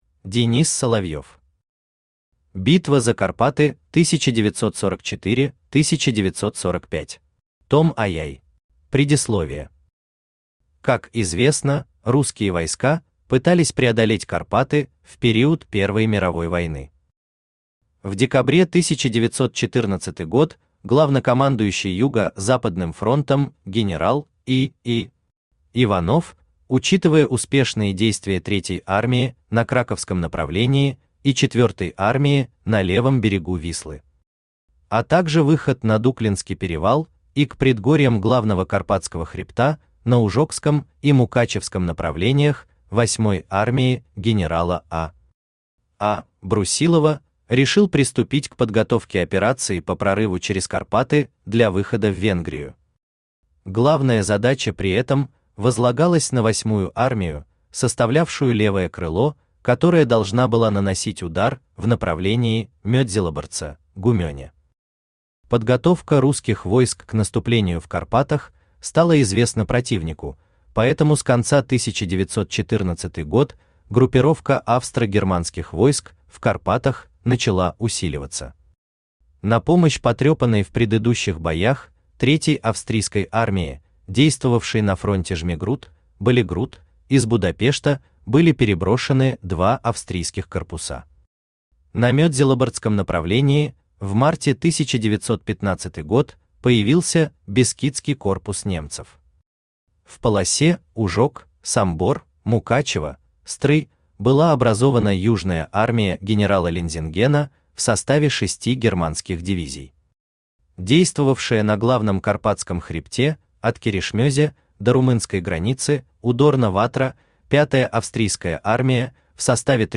Аудиокнига Битва за Карпаты 1944-1945. ТОМ II | Библиотека аудиокниг
ТОМ II Автор Денис Соловьев Читает аудиокнигу Авточтец ЛитРес.